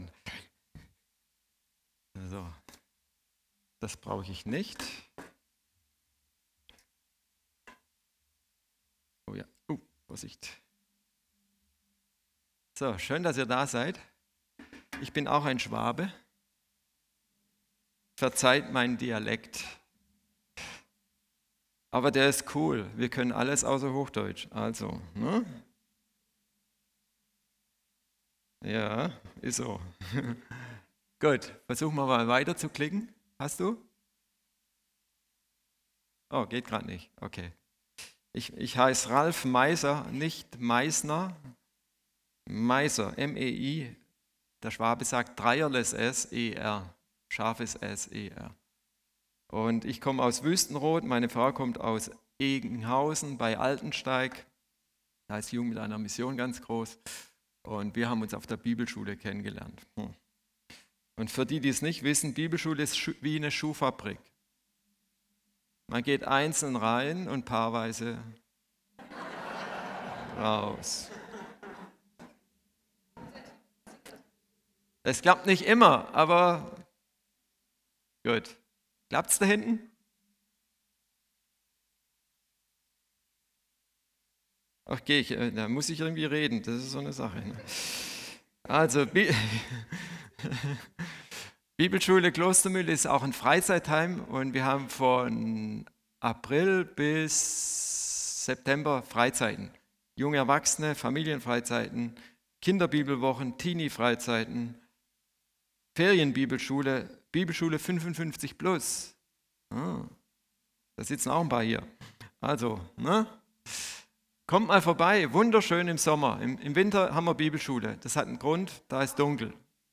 alle Predigten - Stadtmission Solingen